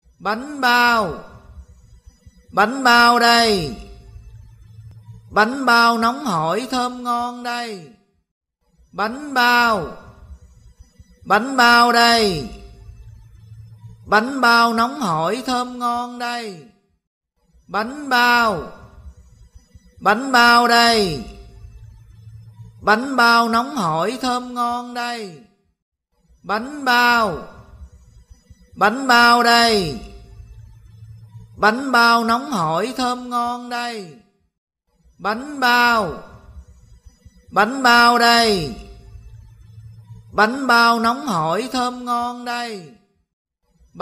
Tiếng rao Bánh bao đây, Bánh bao nóng hổi thơm ngon đây…
Những từ ngữ giản dị nhưng đầy hấp dẫn – bánh bao nóng hổi, bánh bao thơm lừng, bánh bao ngon lành, bánh bao vừa ra lò... Tiếng rao ấy không chỉ là lời chào bán, mà còn là một phần ký ức, một nét âm thanh thân thuộc của đời sống đường phố Việt Nam.
tieng-rao-banh-bao-day-banh-bao-nong-hoi-thom-ngon-day-www_tiengdong_com.mp3